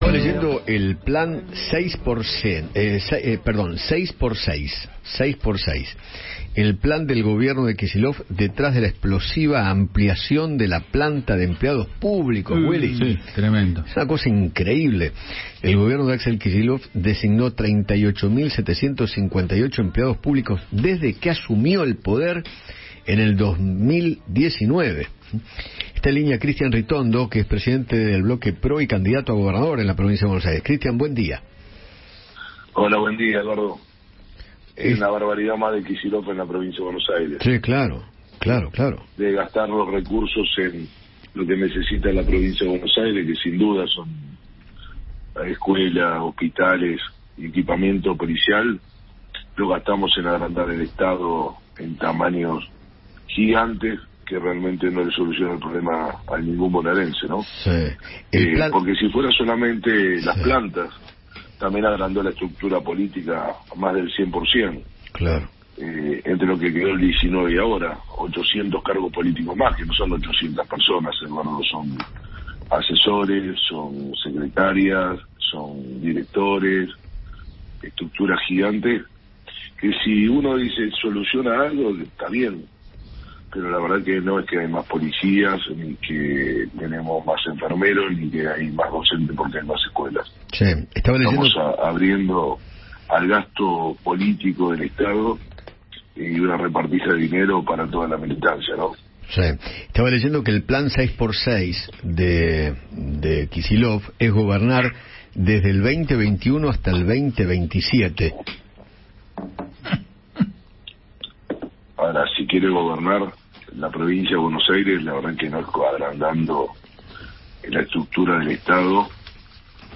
Cristian Ritondo, presidente del bloque PRO, conversó con Eduardo Feinmann sobre el Plan 6×6 que quiere impulsar Axel Kicillof para ser reelegido en la provincia de Buenos Aires.